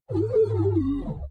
阴险且满足的哔哔声